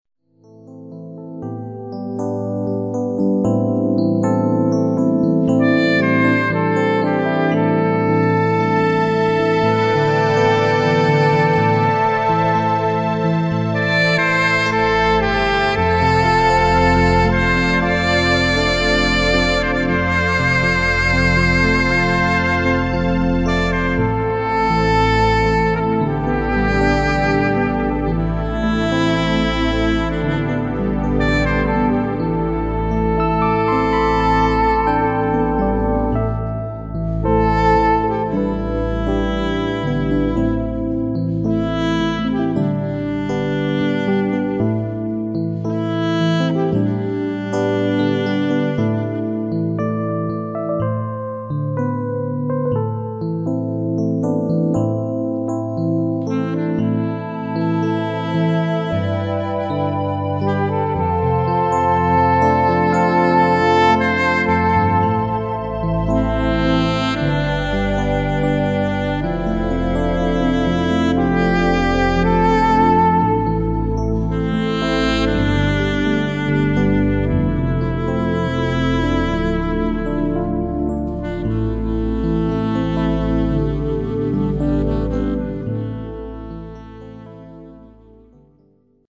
alto saxophone flows gracefully
The entire album was created in this way.
Rich, sensual, intimate and expressive.
Keyboards
Alto Saxophone